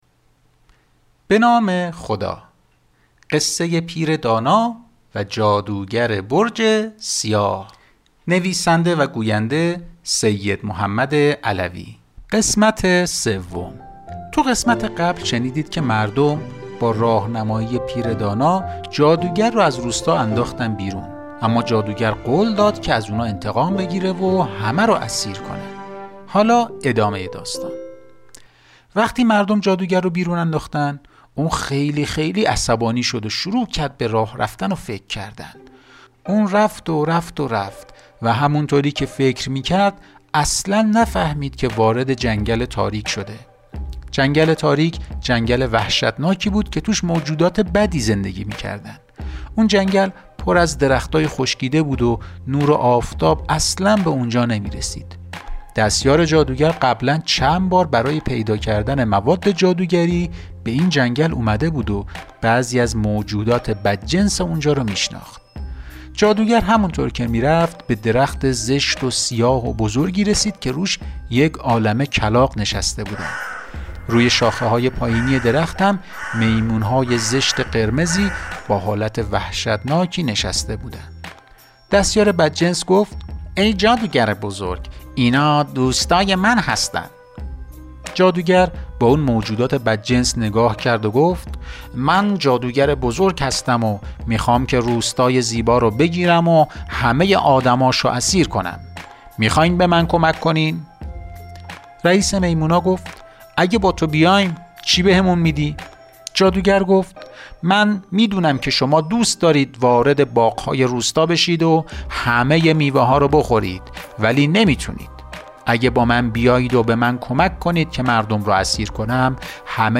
دریافت کامل کتاب صوتی